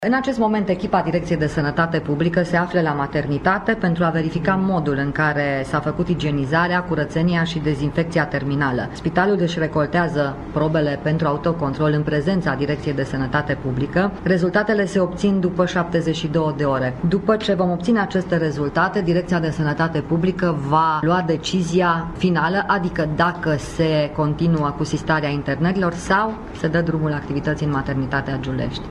Ministrul Sănătăţii, Sorina Pintea a annunţat că joi urmează să se decidă dacă se vor relua internările la această unitate medicală care se află în proces de igienizare.